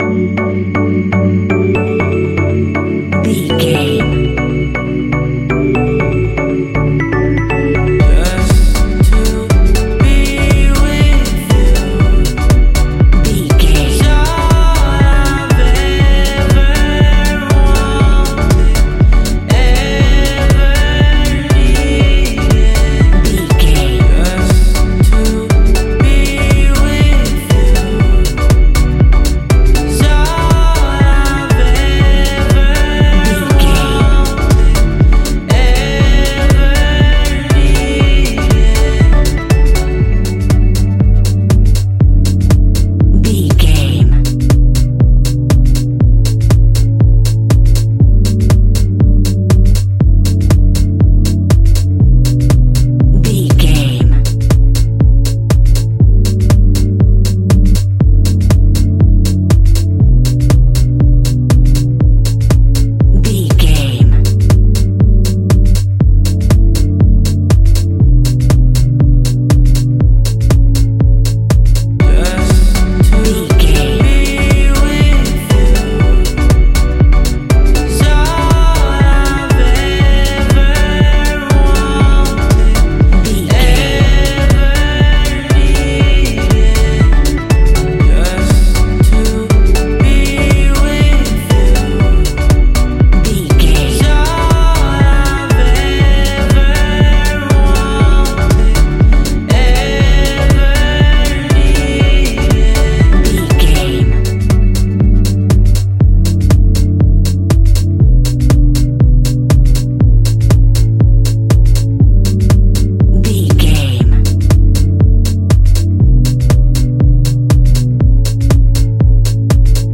Ionian/Major
E♭
house
electro dance
synths
techno
trance